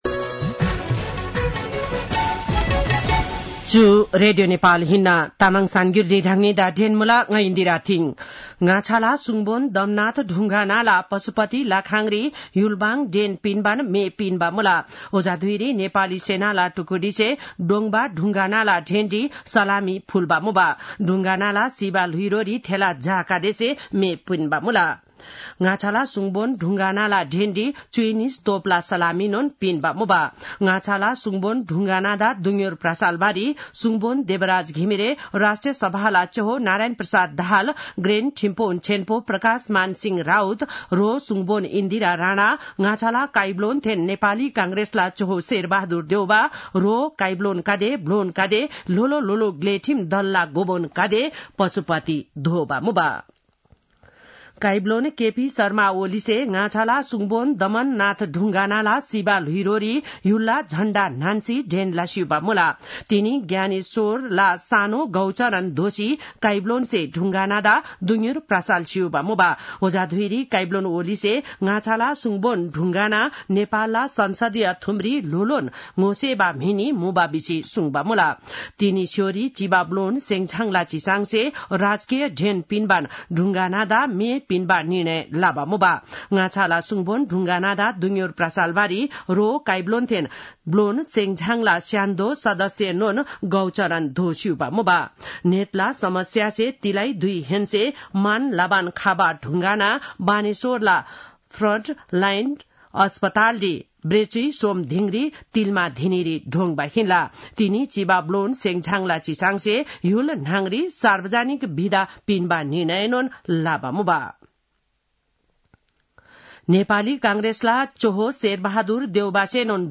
तामाङ भाषाको समाचार : ४ मंसिर , २०८१
Tamang-news-8-02.mp3